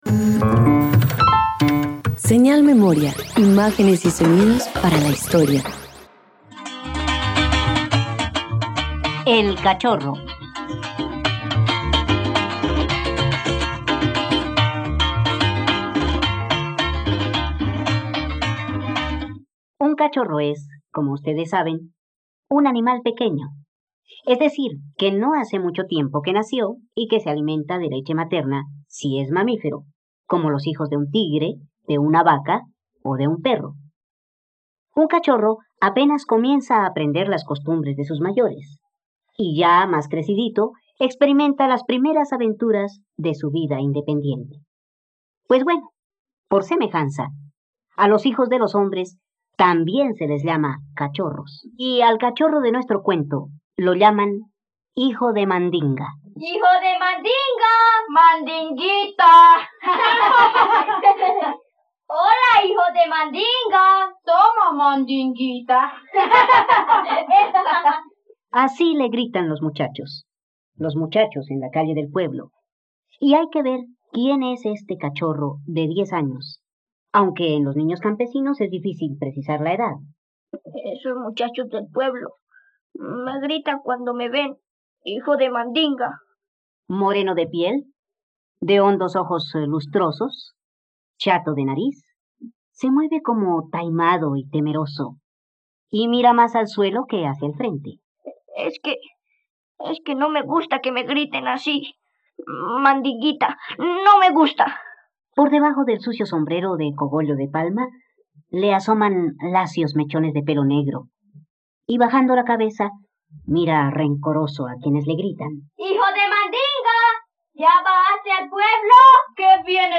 El cachorro - Radioteatro dominical | RTVCPlay